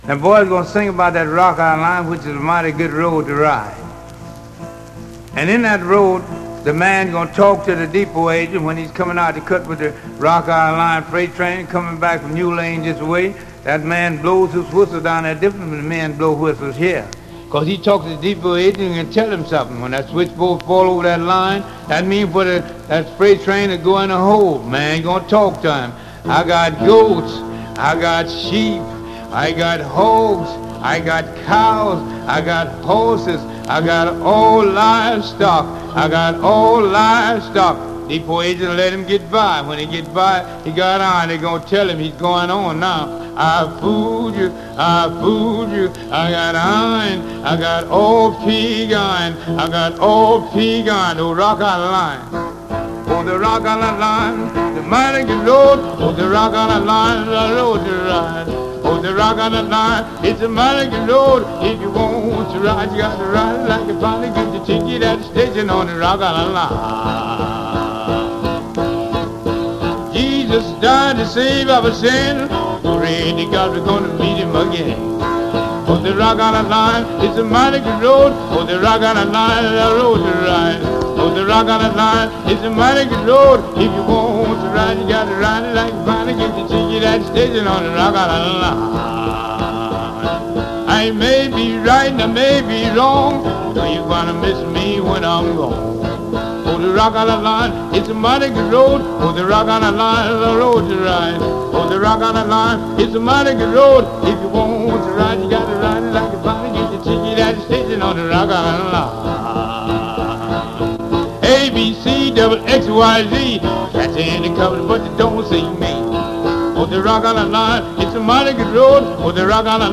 vocals and guitar.
The performance continues to increase in tempo. f